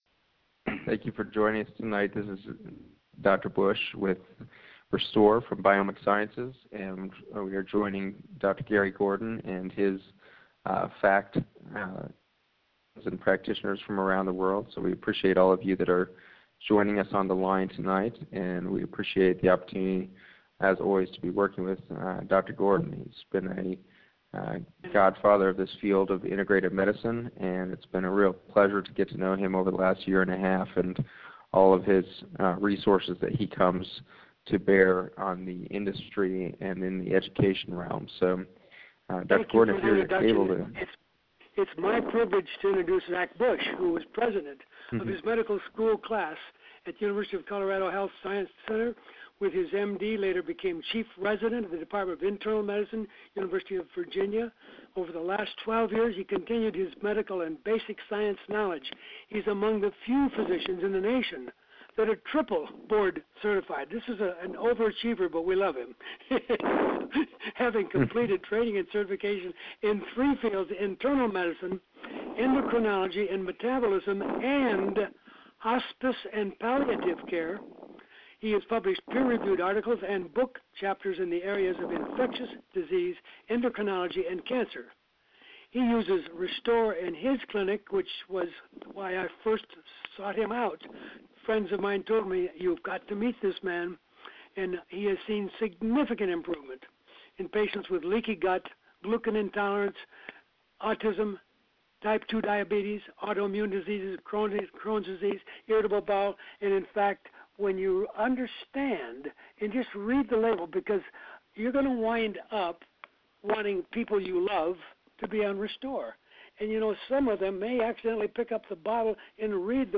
conference-audio-3_B.mp3